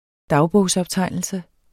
Udtale [ ˈdɑwbɔˀws- ]